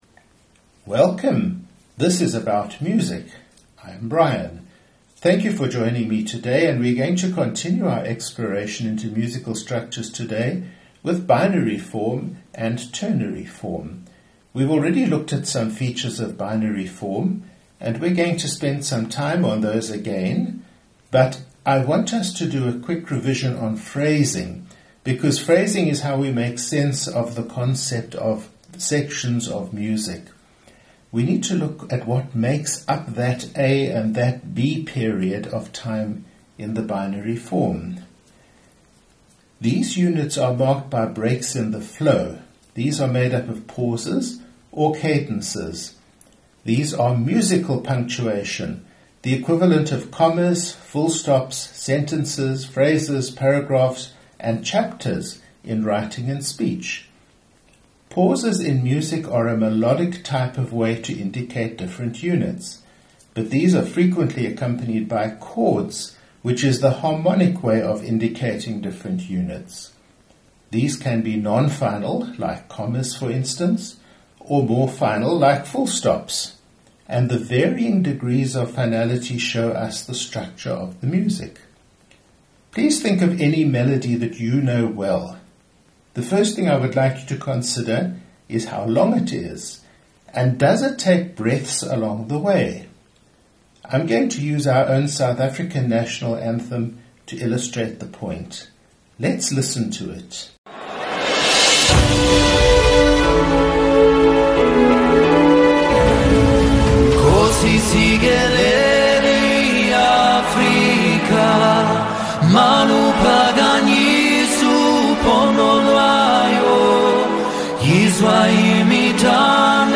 Performed by Orchestre symphonique de Montréal conducted by Charles Dutoit
Performed by Munich Symphony Orchestra with an unnamed conductor